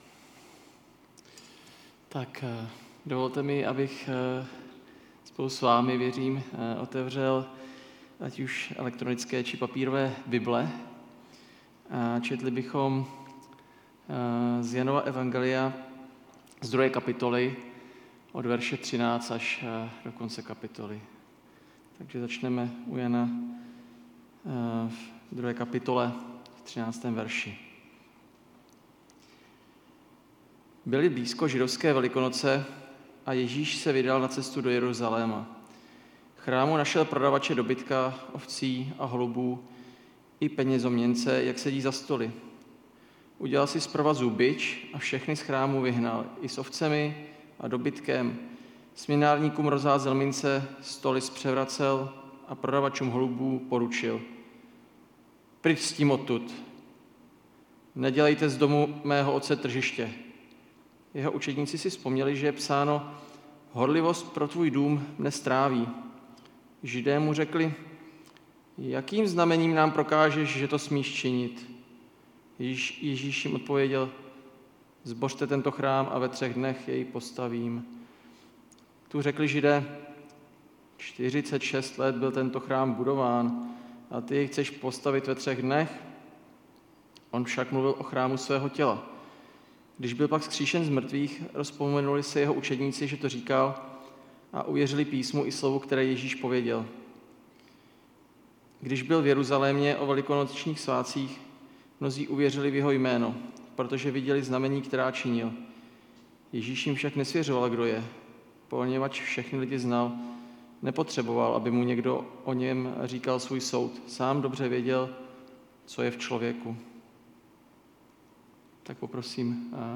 5. kázání ze série Záblesky slávy (Jan 2,13-25)